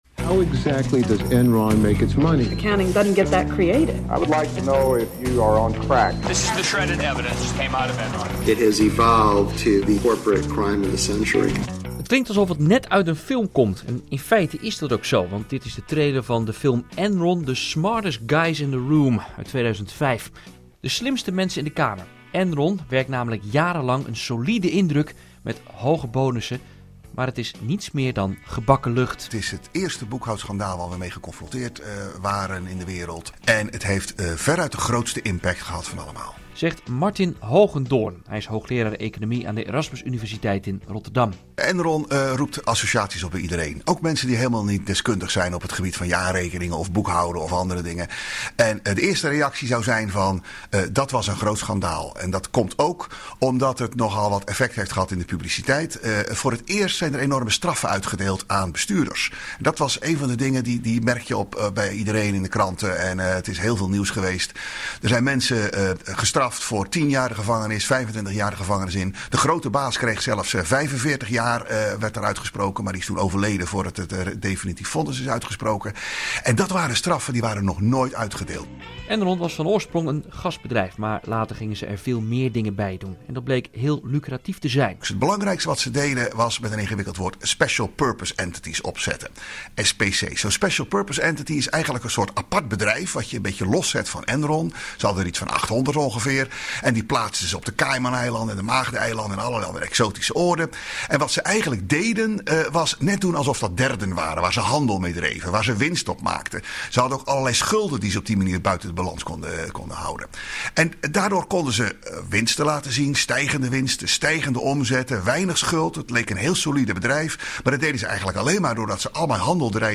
Downloads Interview bij RTV Rijnmond 4.54 MB